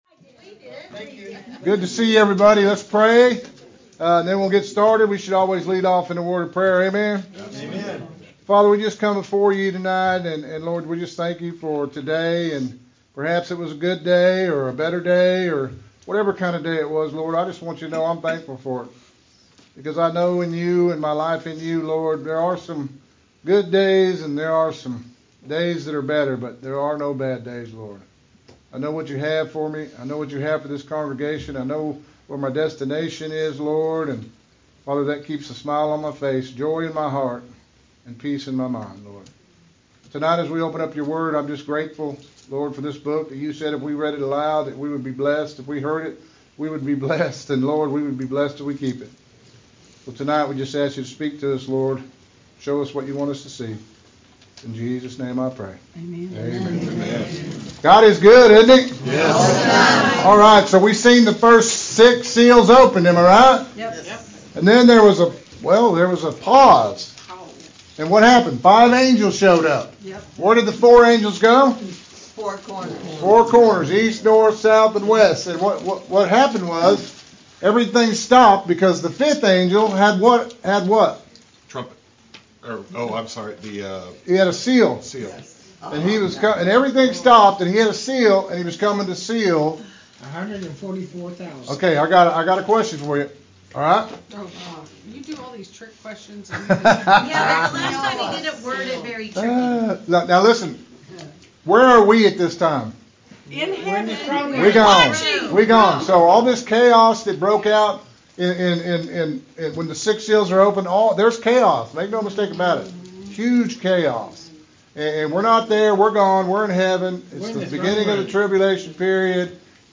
Interactive Bible Study